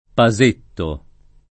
[ pa @% tto ]